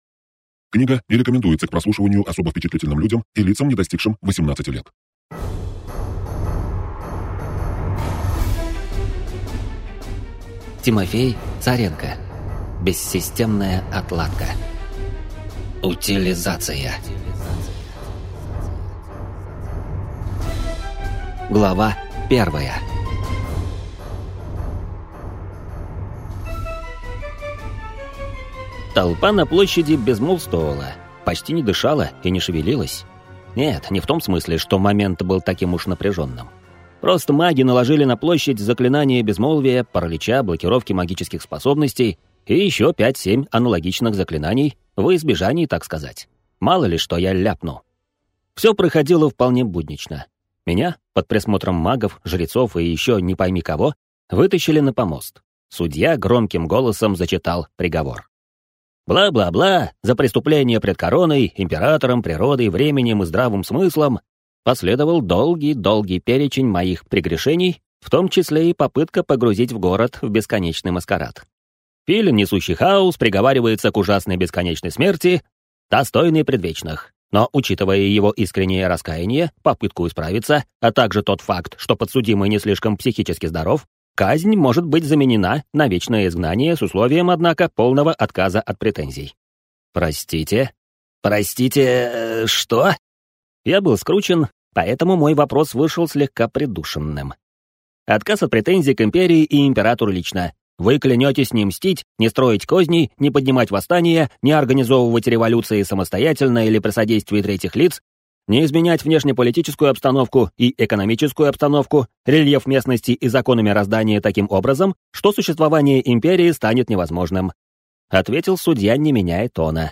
Аудиокнига Бессистемная отладка. Утилизация | Библиотека аудиокниг